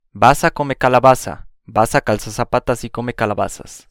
A pronúncia do z no espanhol
Outro grande mistério da língua castelhana é a pronúncia do “z”, que é igual ao “s” na América Latina.